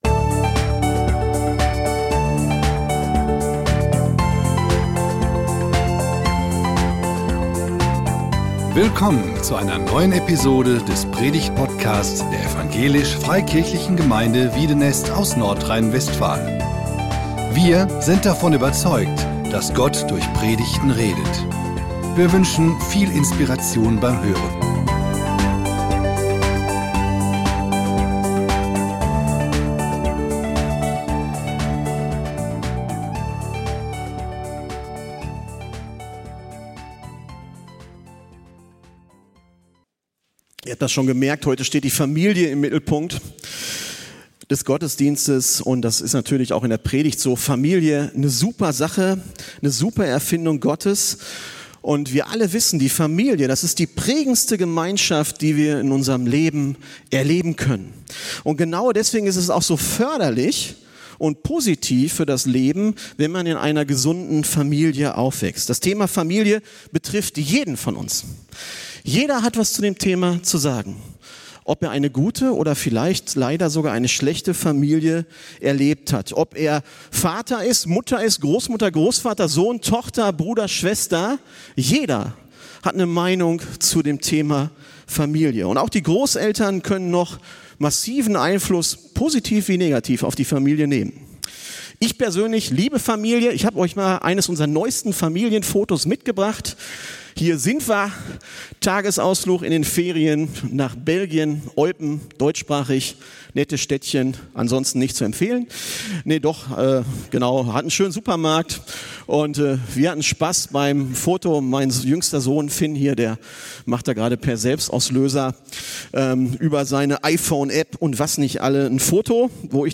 Gute Elternschaft - Predigt